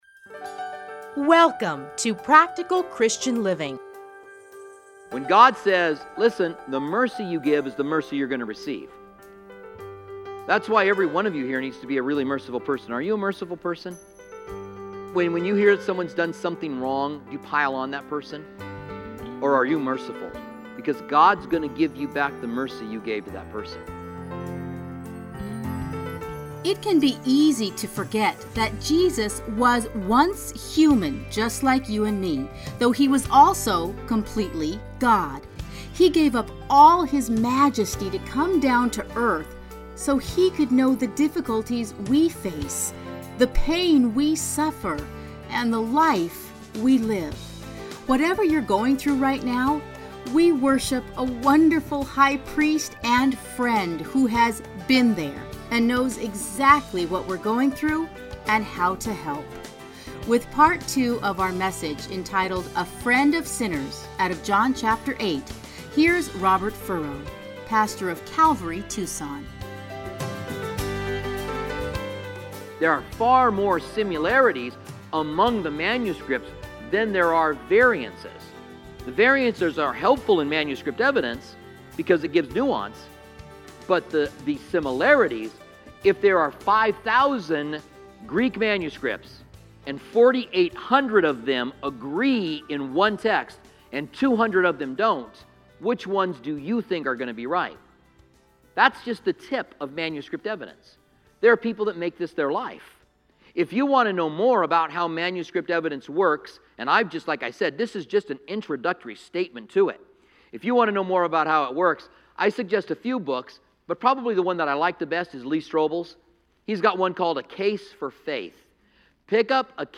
teachings are edited into 30-minute radio programs titled Practical Christian Living.